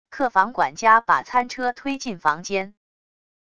客房管家把餐车推进房间wav音频